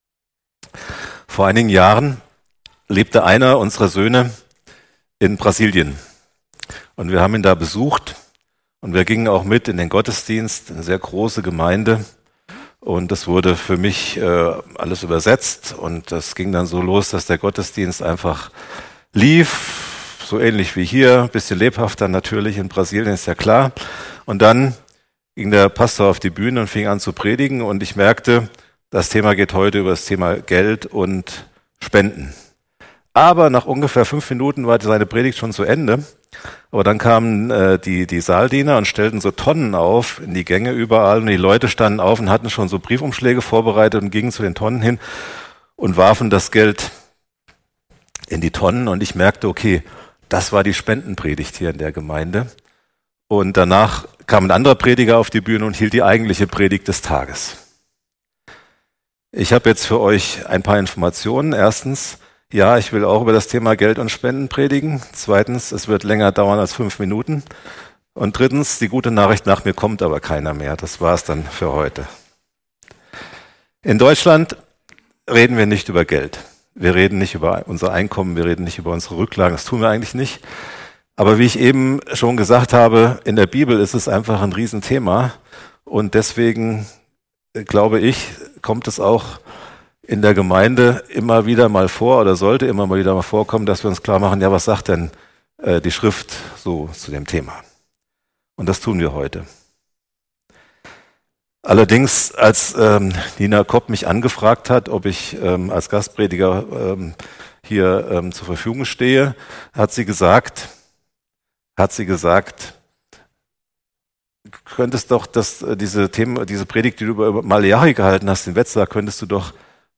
Predigen und andere Vorträge